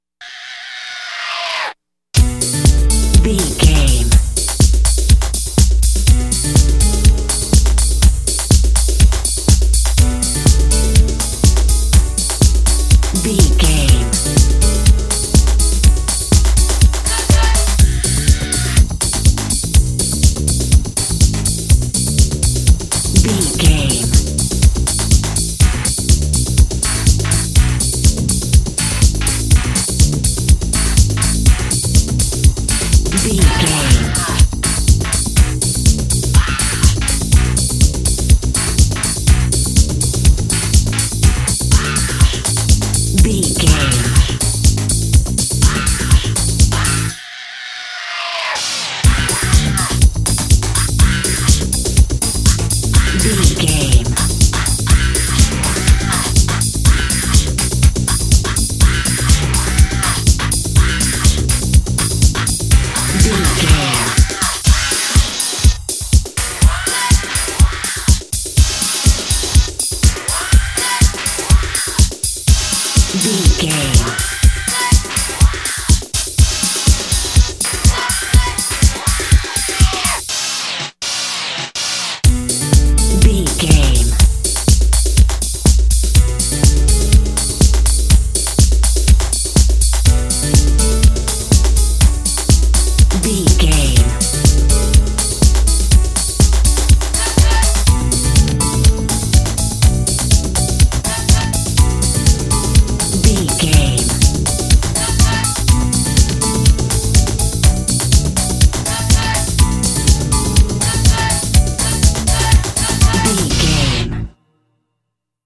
Dorian
Fast
drum machine
synthesiser
electric piano
Eurodance